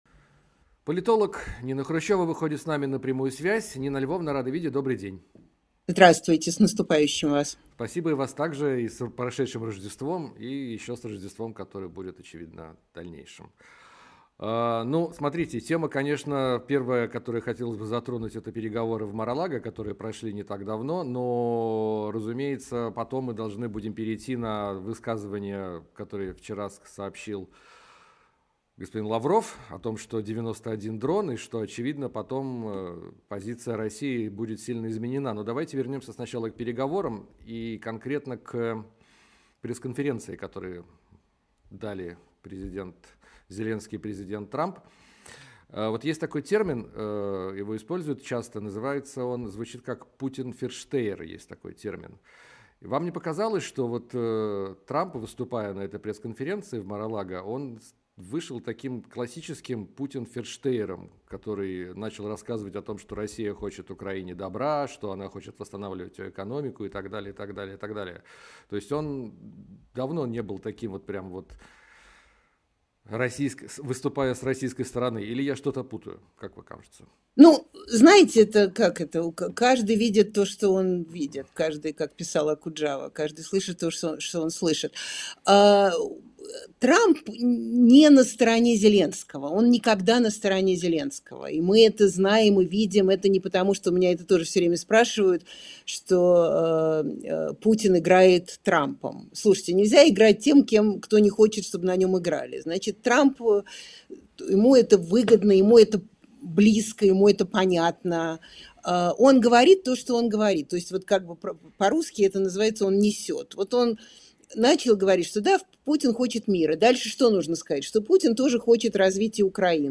Нина Хрущёва политолог